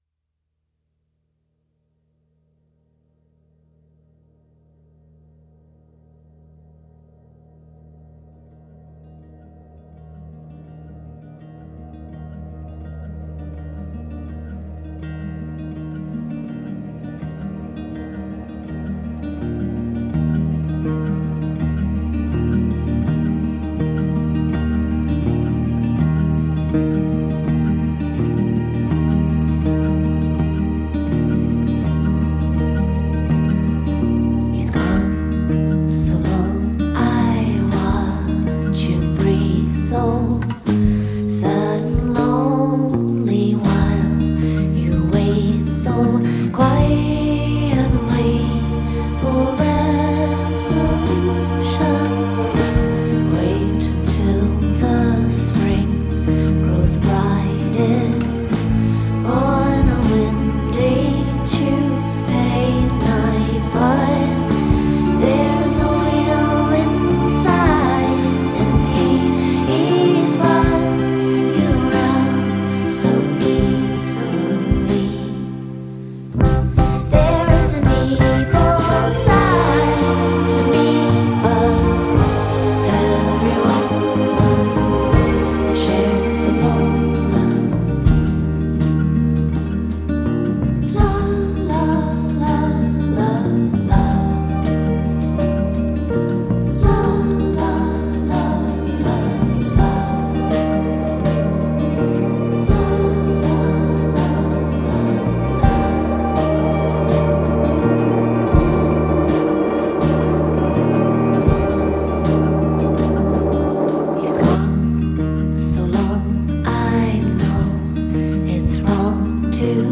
psych-folk sound is both timeless and modern
synths/electronix
flute and background vocals
drums
wistful, melancholic meditation